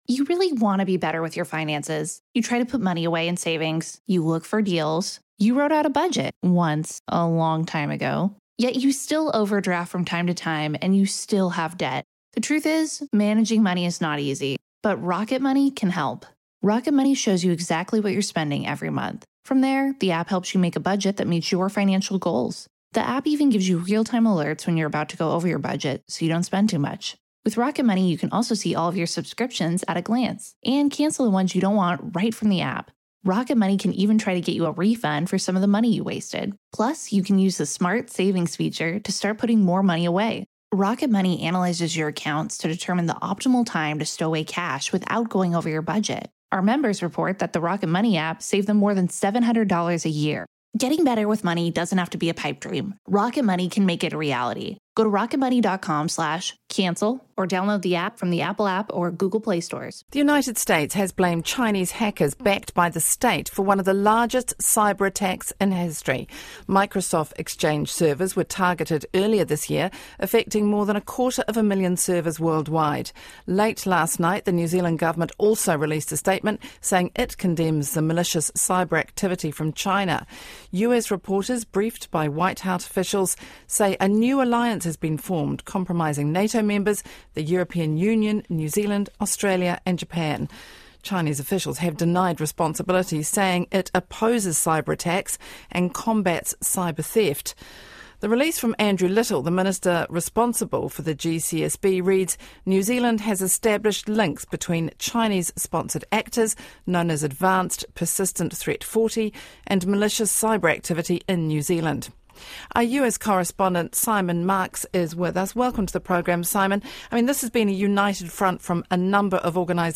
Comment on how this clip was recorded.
live report for Radio New Zealand's "Morning Report"